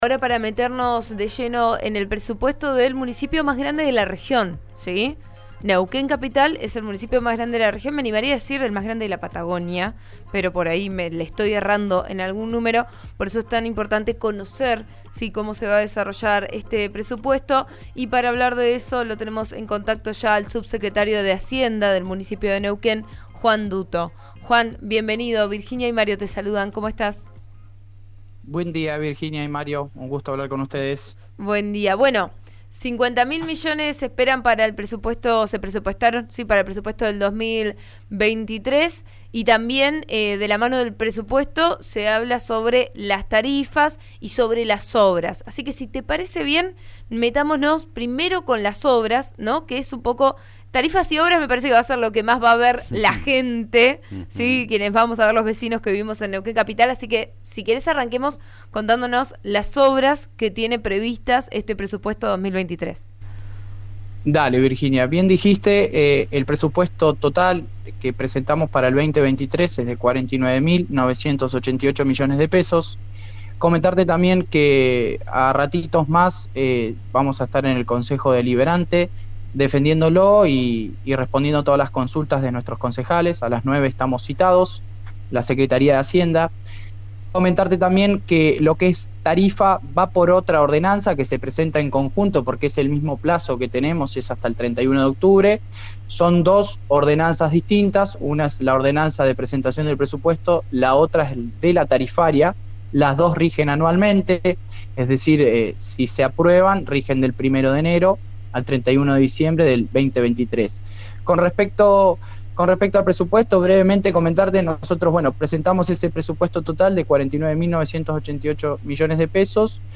Hasta el momento sin definiciones, la legisladora aclaró su postura en una charla con Vos A Diario por RN Radio.